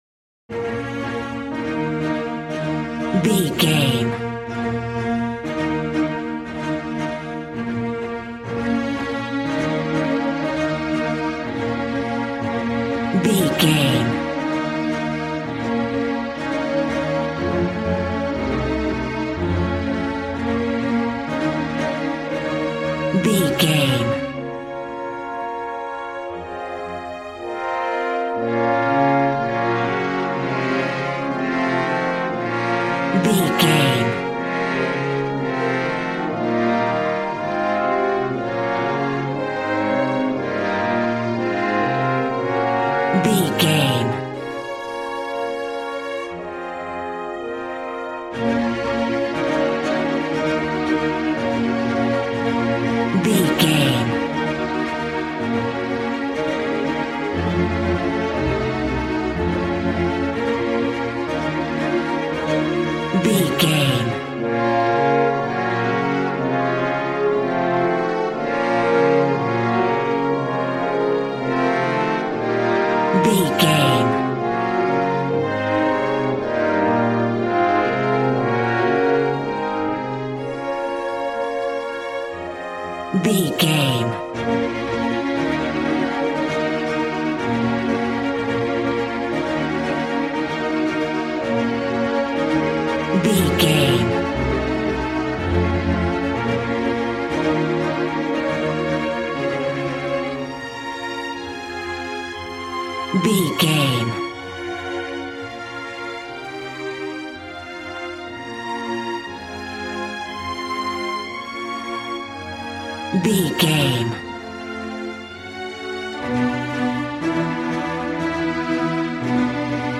A classical music mood from the orchestra.
Regal and romantic, a classy piece of classical music.
Aeolian/Minor
B♭
regal
cello
violin
strings